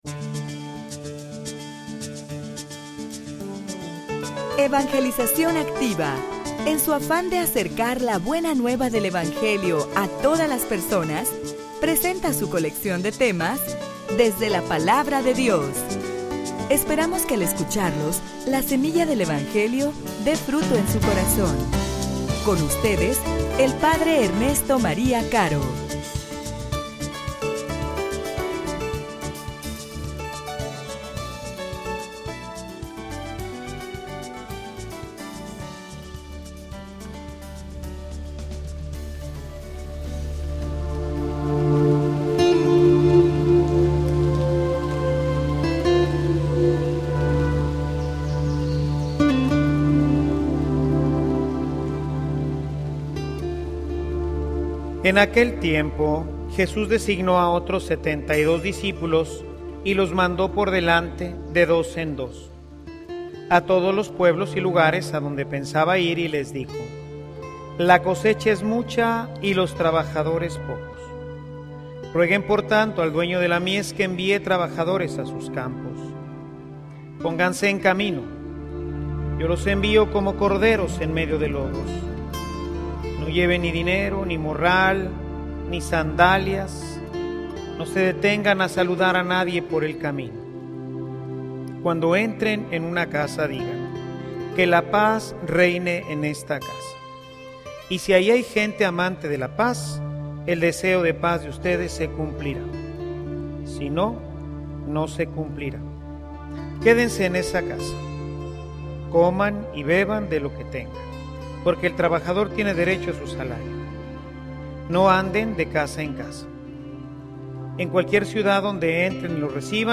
homilia_Su_felicidad_y_salvacion_pueden_depender_de_ti.mp3